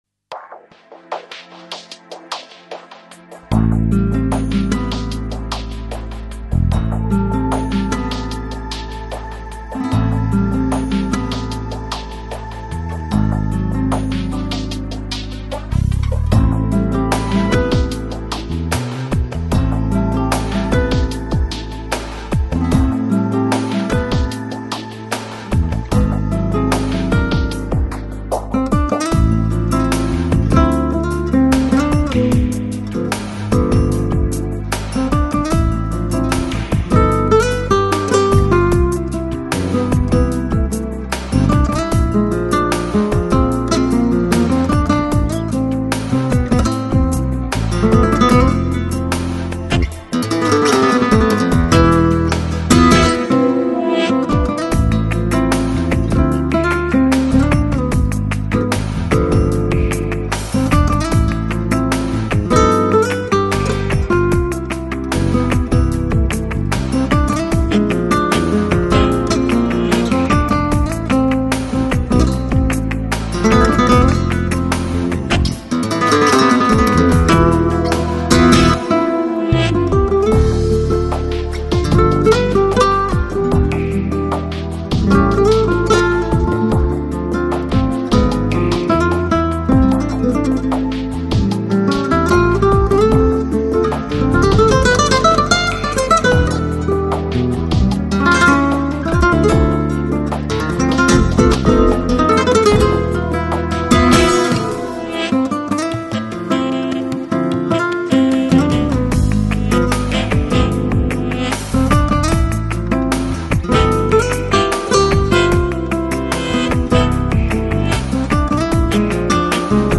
Жанр: Electronic, Lounge, Chill Out, Downtempo, Flamenco